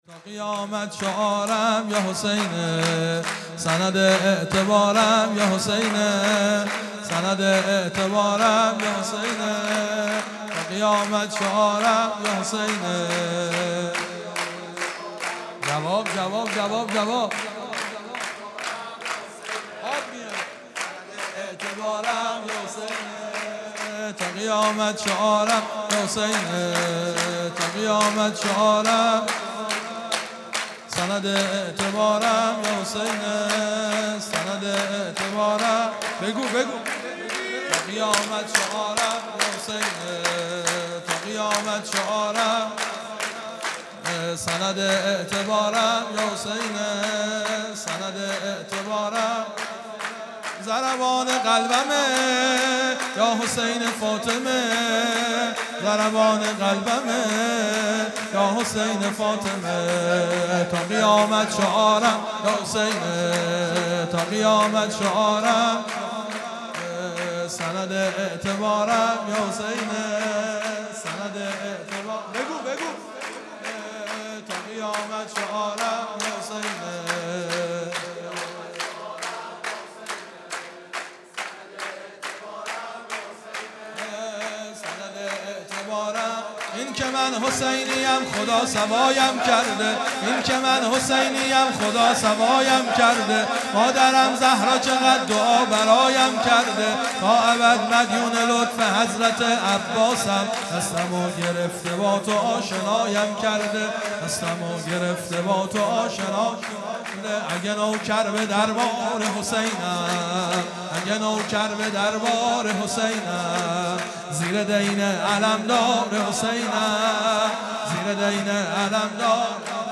سرود
میلاد سرداران کربلا | ۱۲ اردیبهشت ۱۳۹۶